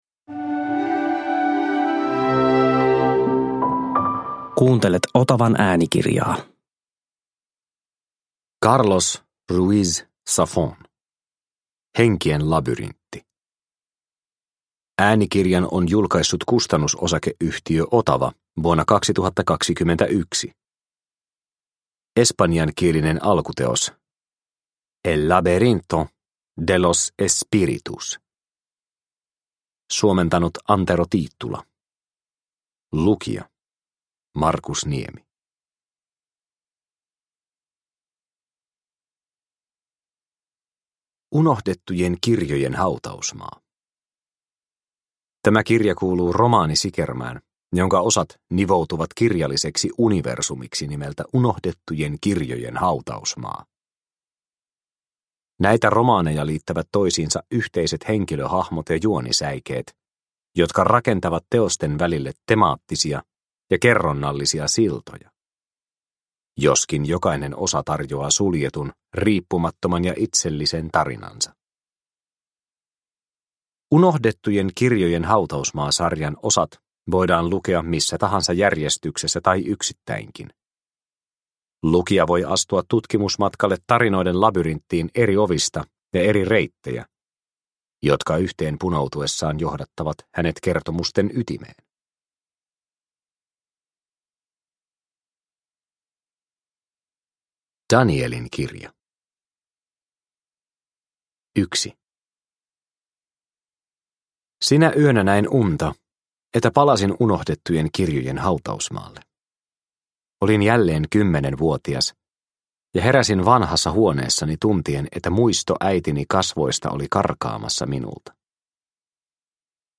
Henkien labyrintti – Ljudbok – Laddas ner